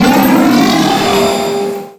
Cri de Solgaleo dans Pokémon Soleil et Lune.